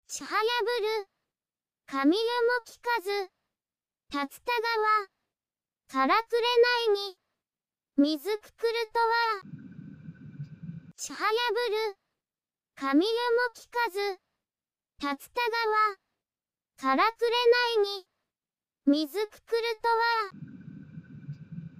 百人一首をさとうささらに詠んでもらった。
一首ごとに２回、ゆっくりめに詠んでもらって、それを全て mp3 にしてみた。